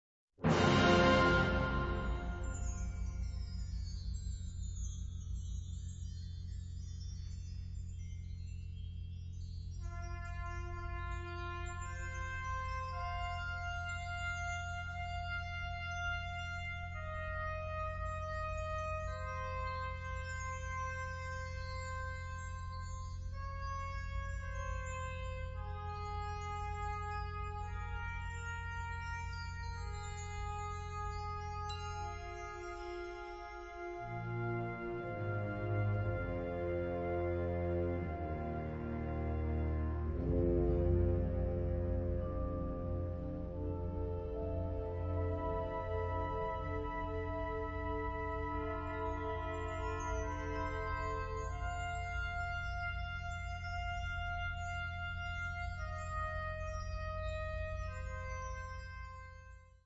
Kategorie Blasorchester/HaFaBra
Unterkategorie Festliche Musik, Fanfare, Hymne
Besetzung Ha (Blasorchester)
Besetzungsart/Infos (Organ obl.)